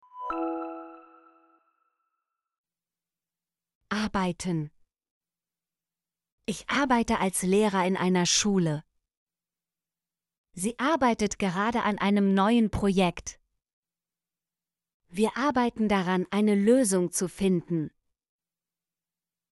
arbeiten - Example Sentences & Pronunciation, German Frequency List